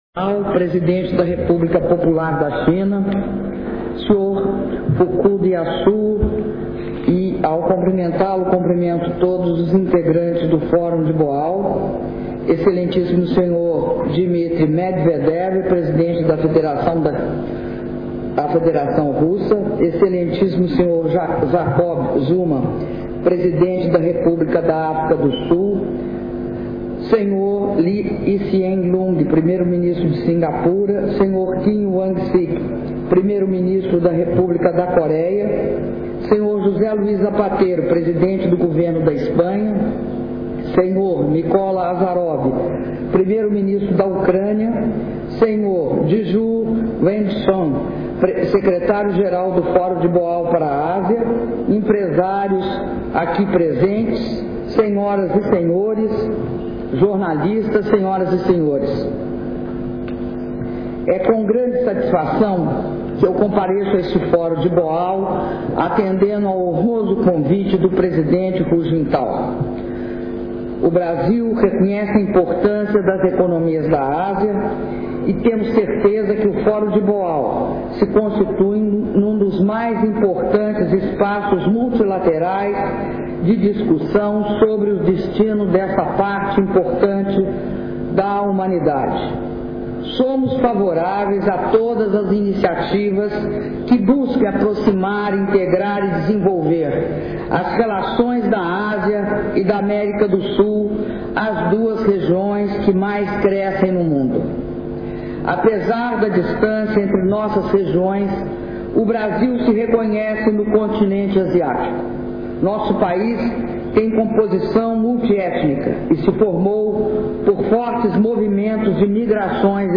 Discurso da Presidenta da República, Dilma Rousseff, durante cerimônia de abertura do Fórum de Boao - Boao/China
Boao-China, 15 de abril de 2011